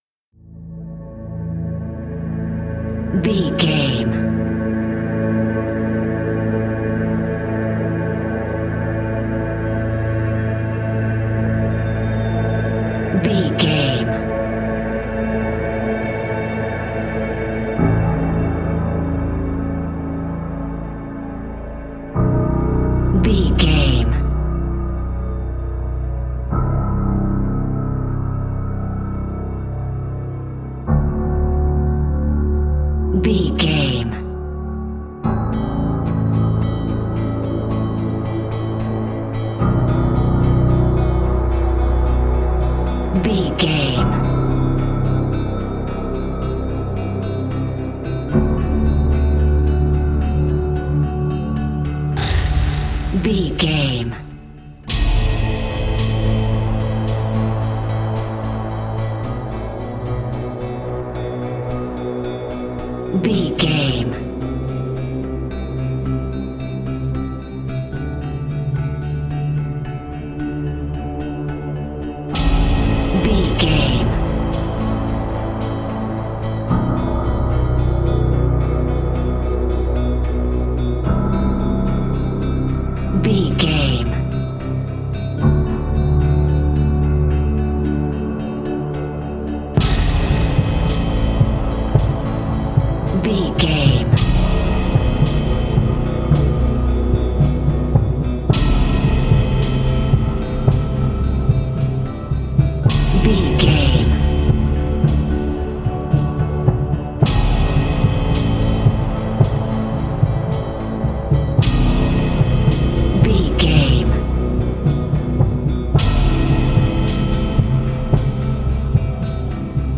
Thriller
Aeolian/Minor
Slow
synthesiser
electric piano
percussion